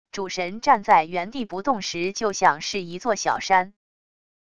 主神站在原地不动时就像是一座小山wav音频生成系统WAV Audio Player